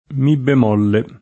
mi [ mi + ] s. m. (nota musicale)